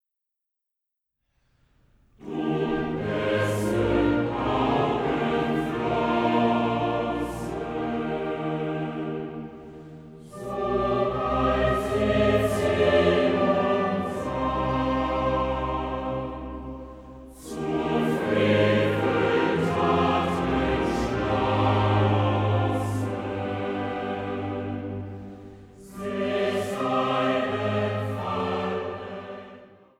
Passionskantate für Soli, Chor und Orchester